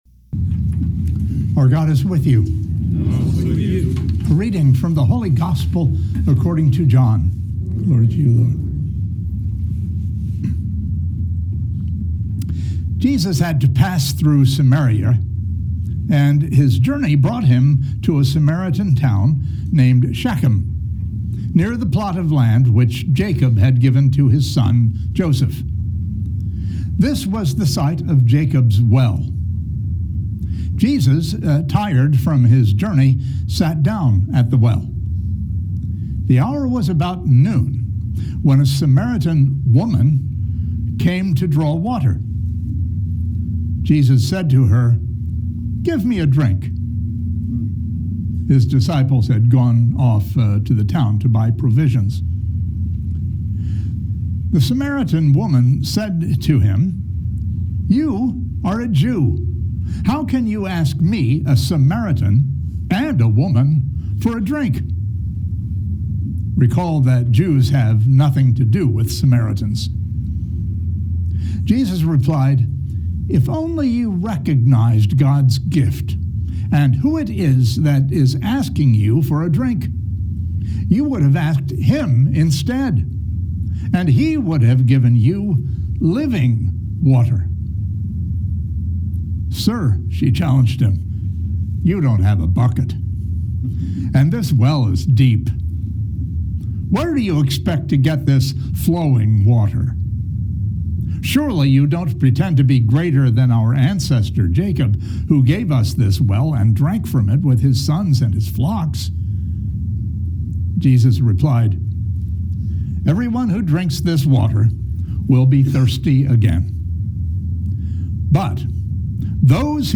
These homilies were offered during our 10a Sun weekly services. Each homily is preceded by the Gospel reading and followed by discussion.